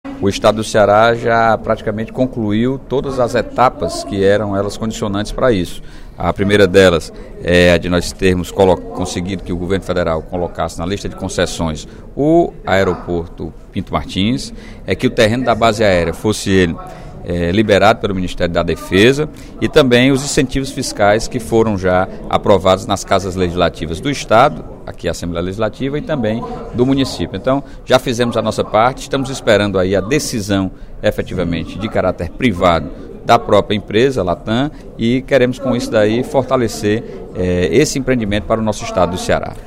O deputado Sérgio Aguiar (PDT) destacou, durante o primeiro expediente da sessão plenária desta terça-feira (14/06), mais uma estratégia do Governo do Estado e da Prefeitura de Fortaleza no sentido de atrair o Centro Internacional de Conexões de Voos (Hub) da Latam – fusão entre a chilena LAN e a brasileira TAM – para o Ceará.